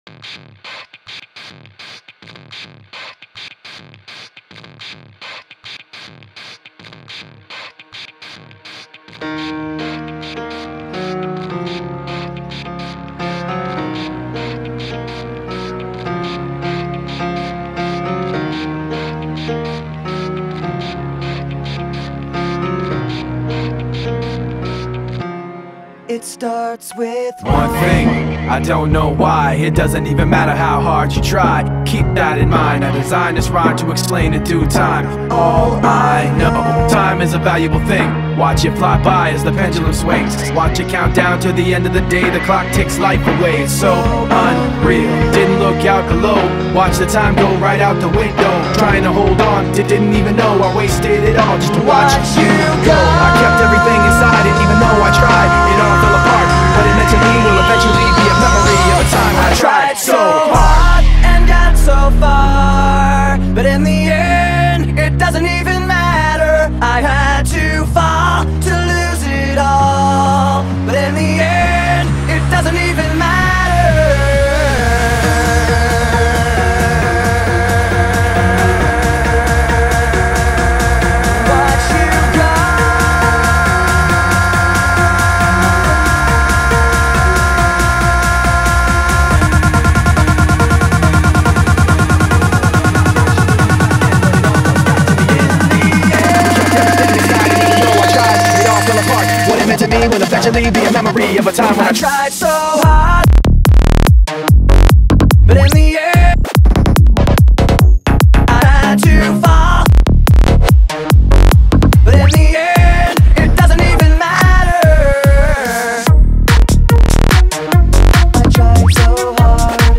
2024-10-23 19:18:51 Gênero: Rock Views